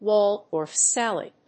音節wál・dorf sálad 発音記号・読み方
/wˈɔːldɔɚf‐(米国英語)/